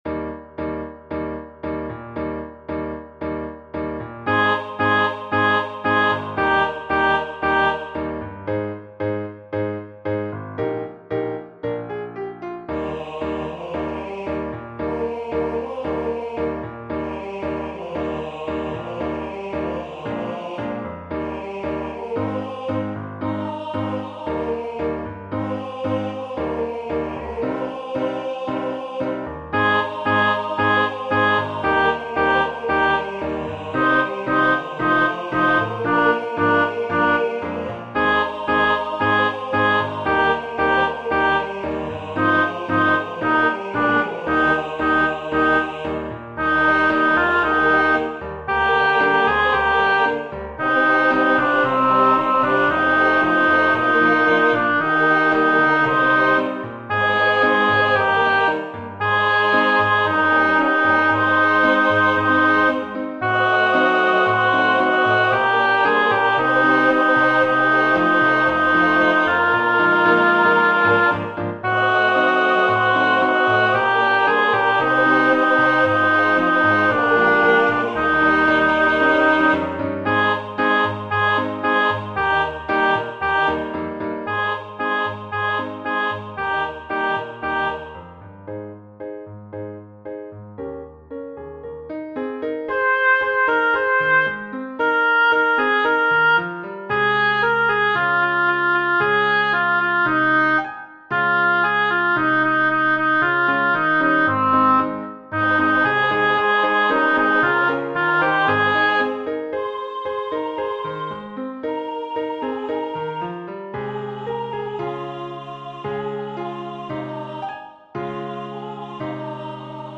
音取り音源 弘田龍太郎作品集Sop.mp3